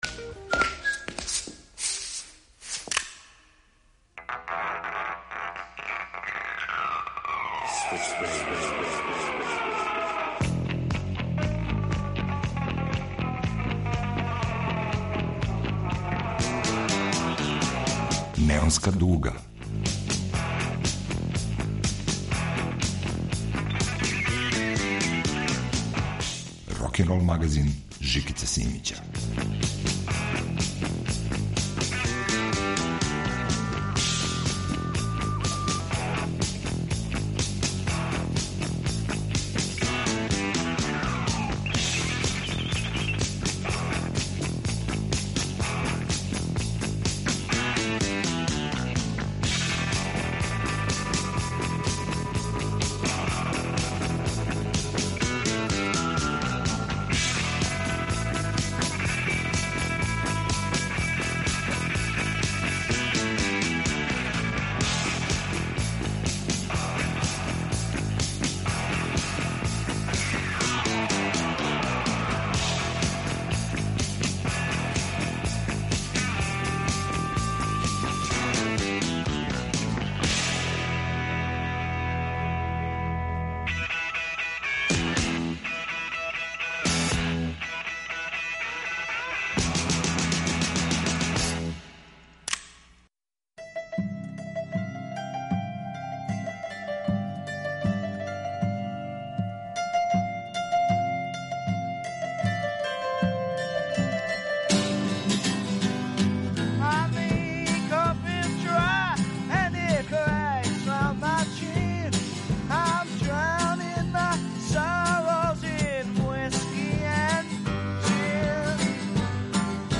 Рокенрол као музички скор за живот на дивљој страни.